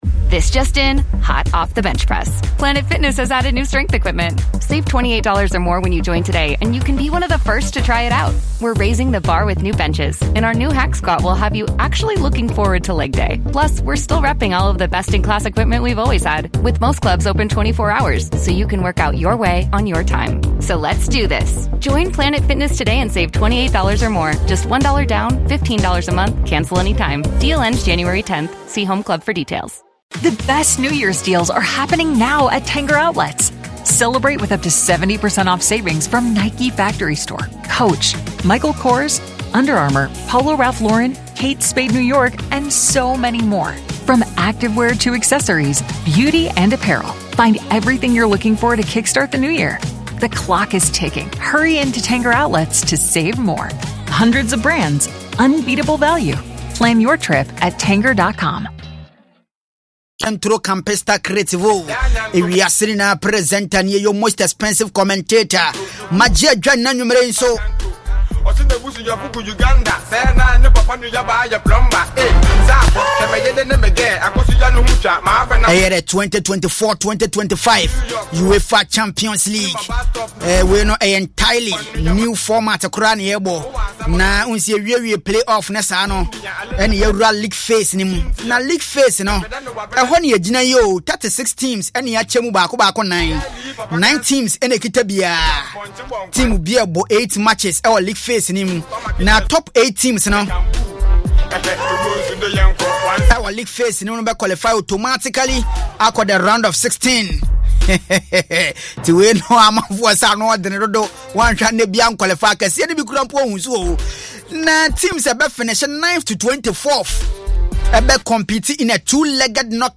A humour-packed sports update show with foreign sports, news tit-bits and special interviews